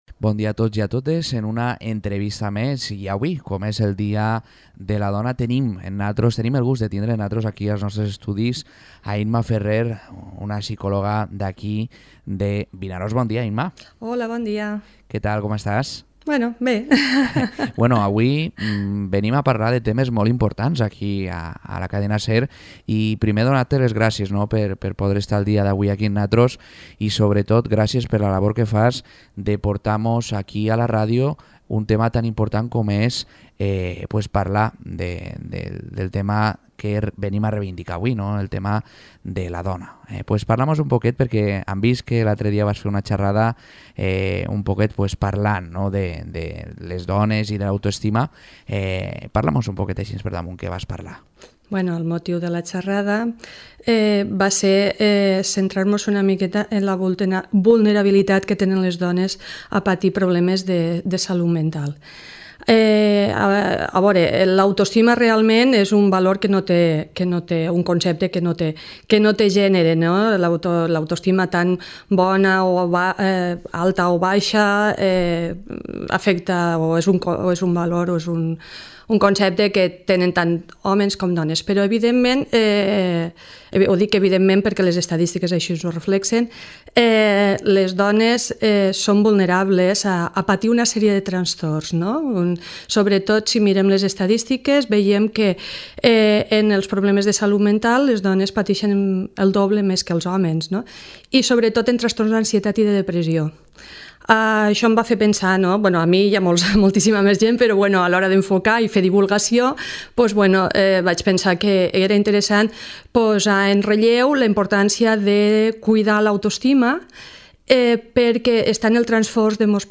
Entrevistes en motiu del 8 de març